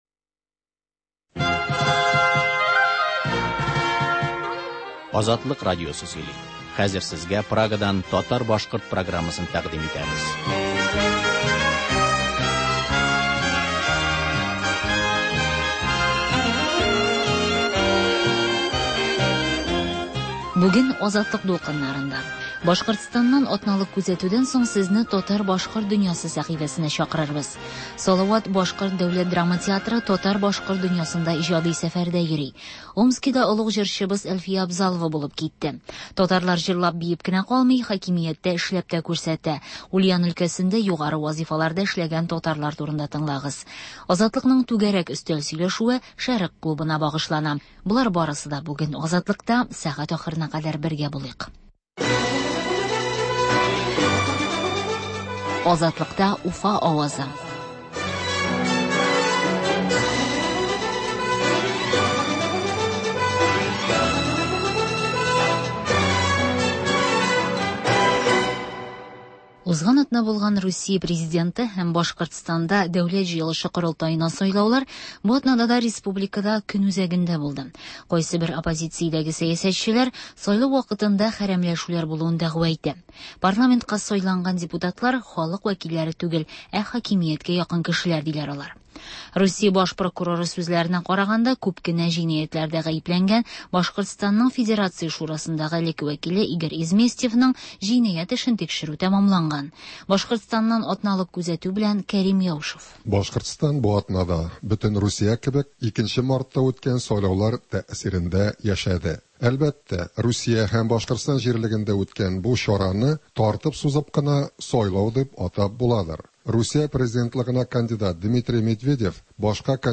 Азатлык радиосы бар атнага күз сала - Башкортстаннан атналык күзәтү - түгәрәк өстәл артында сөйләшү